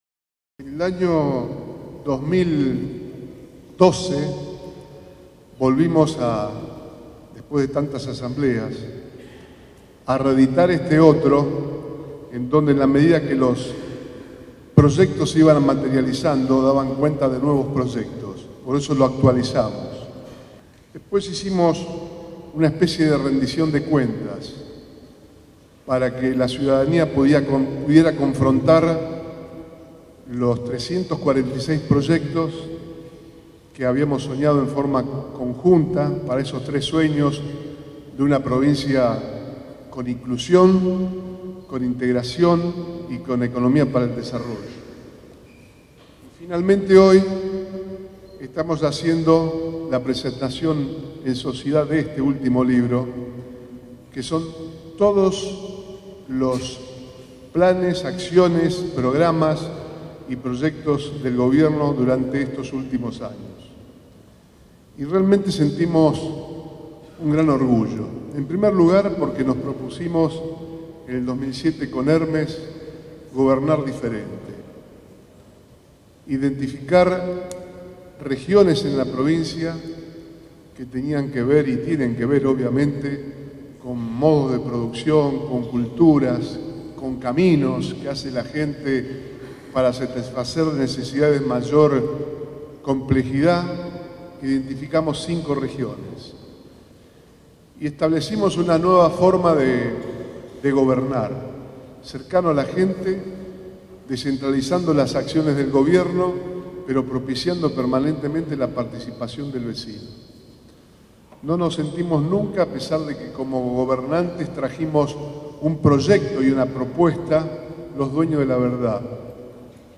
El gobernador Antonio Bonfatti encabezó la asamblea ciudadana que se realizó en la escuela Nº 1245 "Bernardo Houssay" de la ciudad de Casilda, y que formó parte de la segunda ronda de encuentros del año 2015, que se llevan a cabo en ciudades de las cinco regiones de la provincia.
“Estamos cerrando una etapa pero el camino continúa. Sentimos un gran orgullo, porque nos propusimos identificar regiones y establecimos una nueva forma de gobernar, propiciando la participación”, aseguró Bonfatti en el inicio de su discurso.